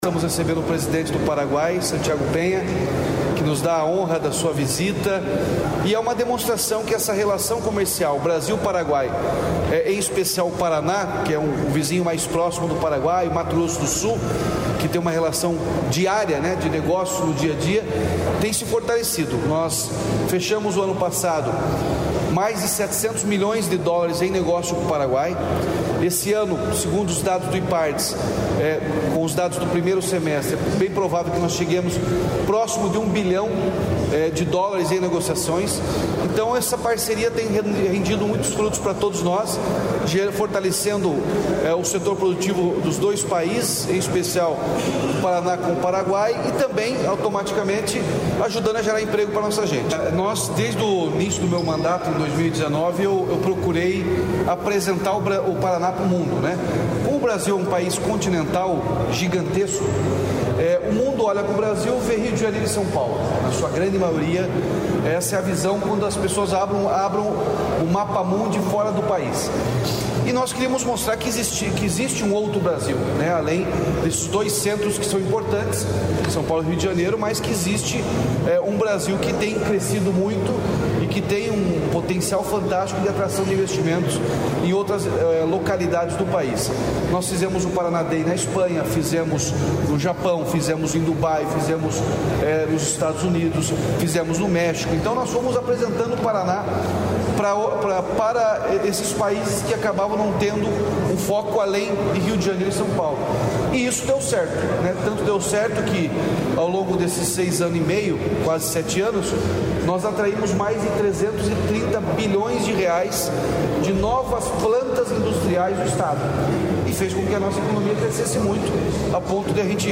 Sonora do governador Ratinho Junior sobre a parceria com o Paraguai